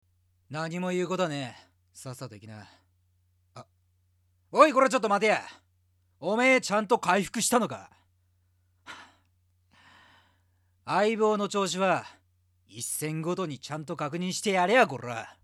口調は荒いが、言ってる事は優しかったりする。
【イメージボイス】